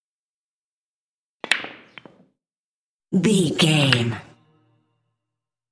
Billards break initial hit
Sound Effects
hard